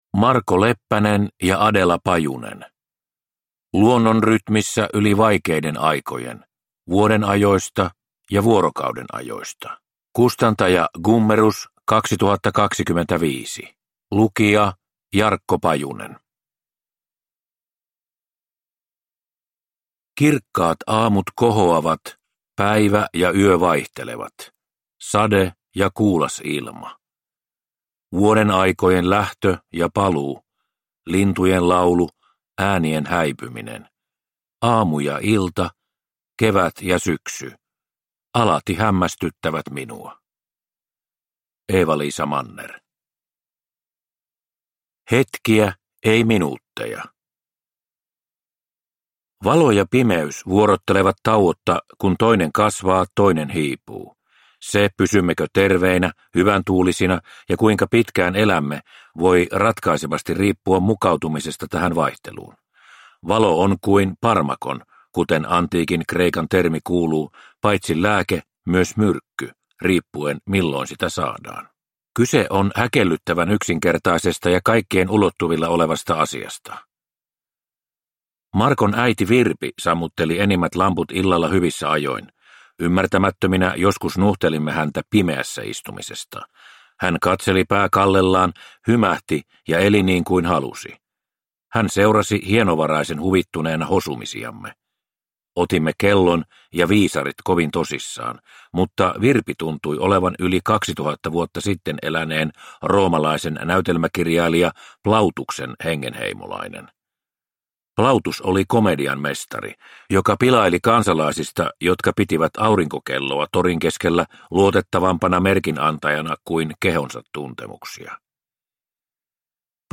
Luonnon rytmissä yli vaikeiden aikojen – Ljudbok